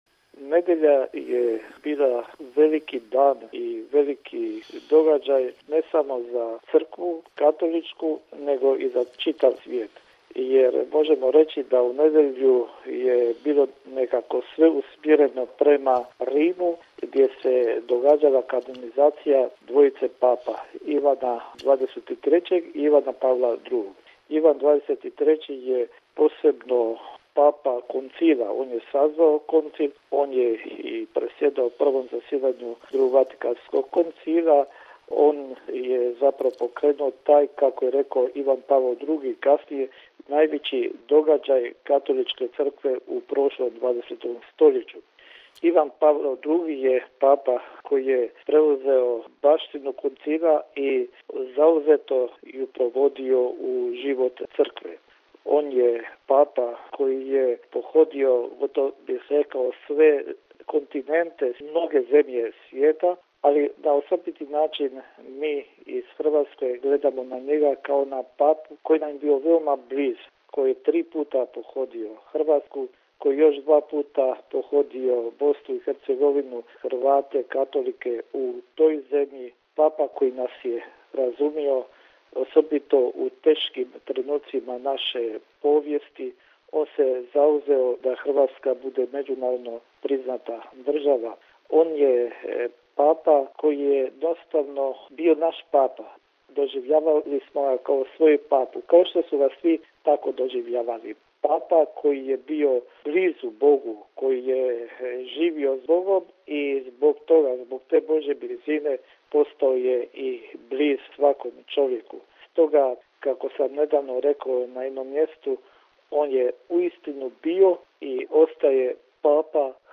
O značaju nedavne kanonizacije dvojice papa te podrobnije o Papi obitelji, Ivanu Pavlu II., koji je više prisutan u sjećanju naše šire hrvatske javnosti, kao i o zahvalnosti Svetom Ocu, za dvojicu novih Svetaca, poslušajmo u telefonskom intervjuu s Uzoritim kardinalom Josipom Bozanićem, koji je i sam nazočio kanonizaciji.